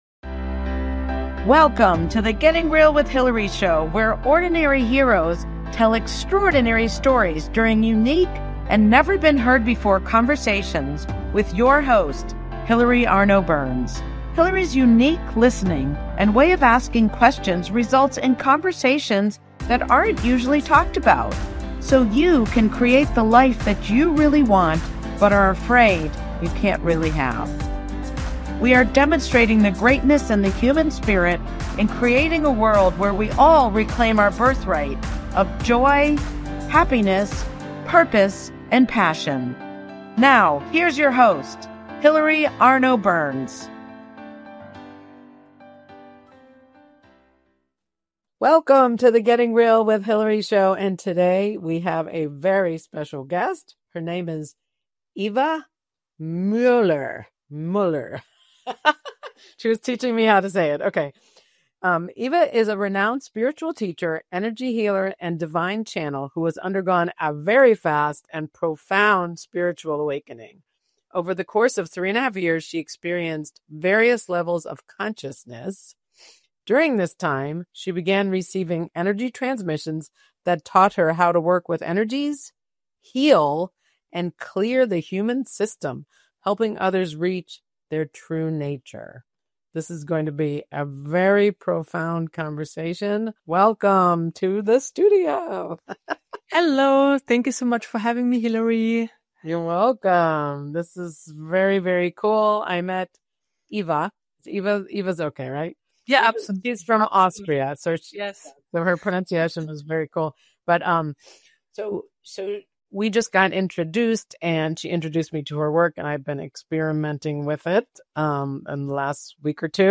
We did a meditation/energy transmission at the end that brought me into a new place.
Please watch our interview. You will experience her energy transmission towards the end.